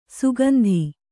♪ sugandhi